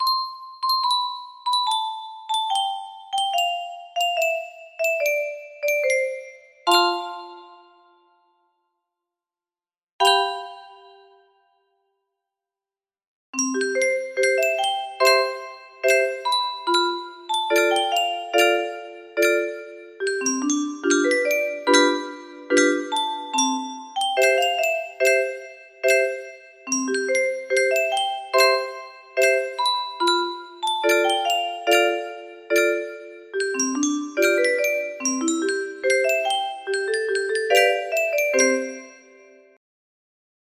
Maiden Prayer 15 music box melody